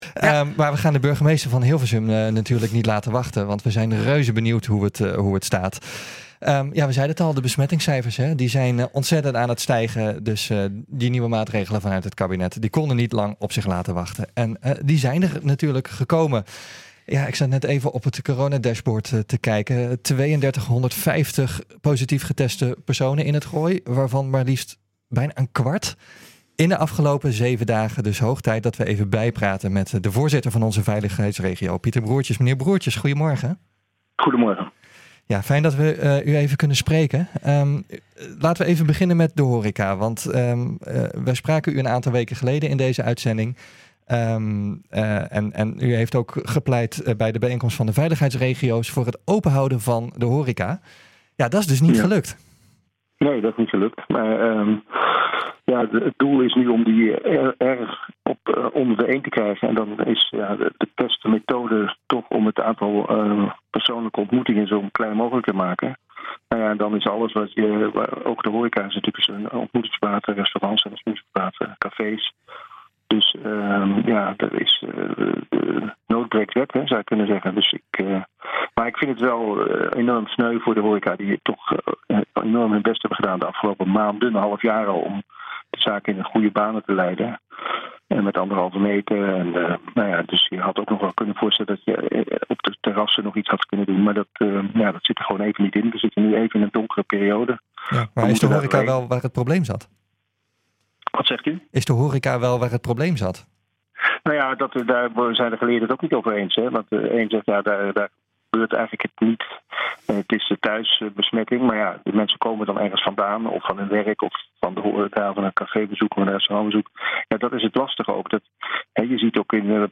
We gaan het erover hebben met de burgemeester van Hilversum en de voorzitter van de veiligheidsregio, Pieter Broertjes.